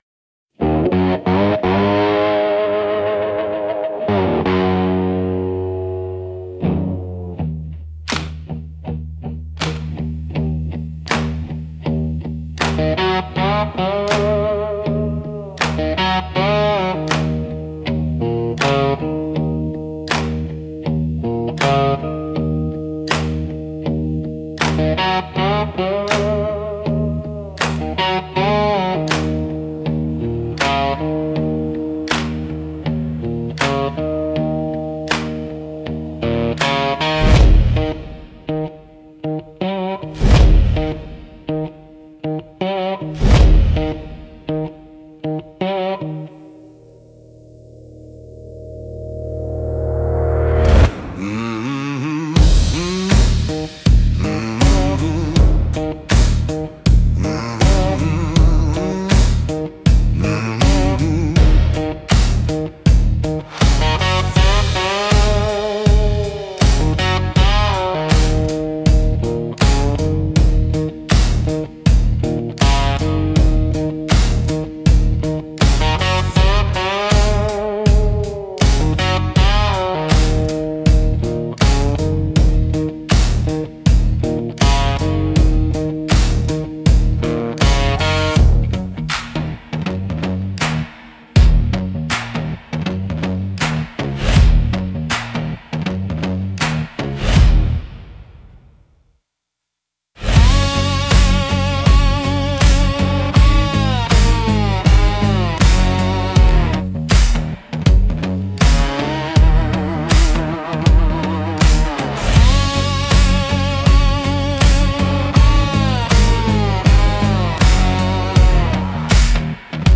Dark Country Groove